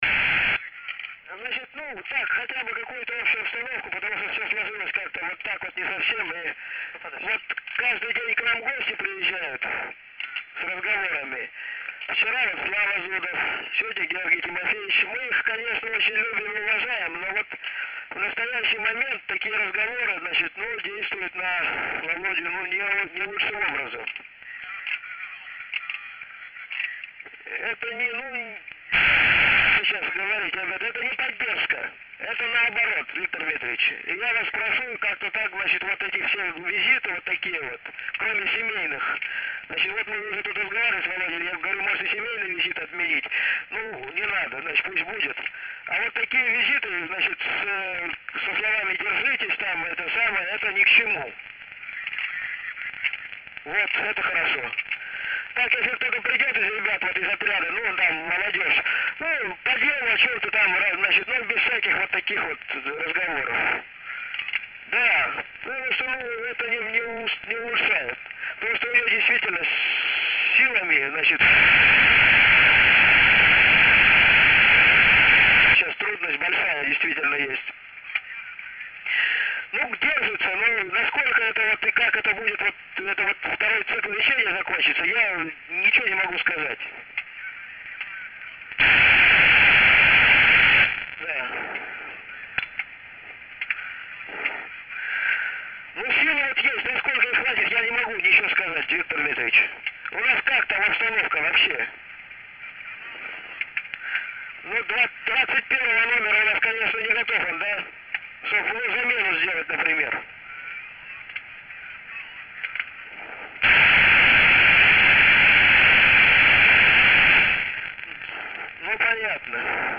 This code line certainly descrambled to voice.
Scrambled part as it sounded scrambled:
As anyone can hear it is not easy to pick out individual words, especially for someone who does not speak Russian fluently.